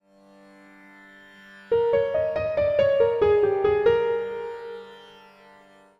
Pool A Vocals Clips (AV-x) Pool A Instrumental Clips (AI-x) Clip AV-1 Clip AI-1 Clip AV-2 Clip AI-2 Clip AV-3 Clip AI-3 Clip AV-4 Clip AI-4 Clip AV-5 Clip AI-5 - Clip AI-6 - Clip AI-7 - Clip AI-8 - Clip AI-9 - Clip AI-10